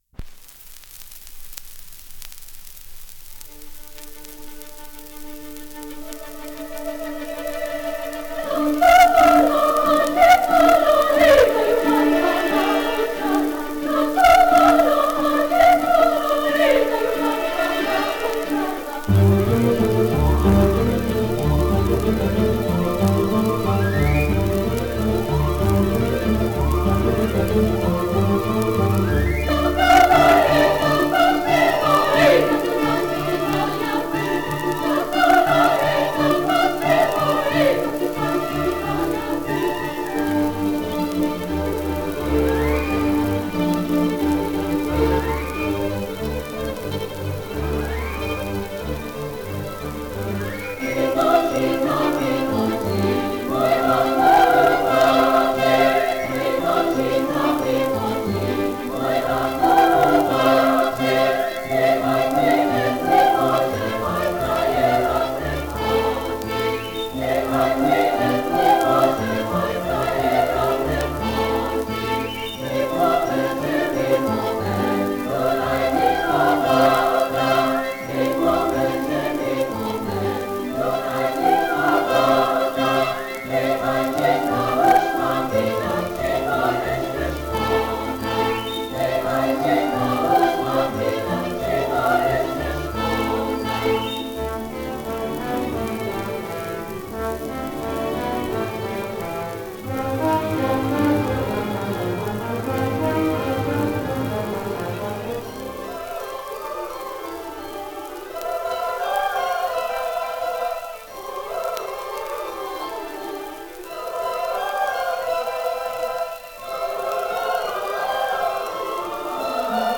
(hlavný názov) Z filmu Matice slovenskej „Zem spieva“ (podnázov) Dátum a miesto nahrávania: 15.10.1933, Praha Popis Ženský zborový spev so sprievodom orchestra.
slovenské ľudové piesne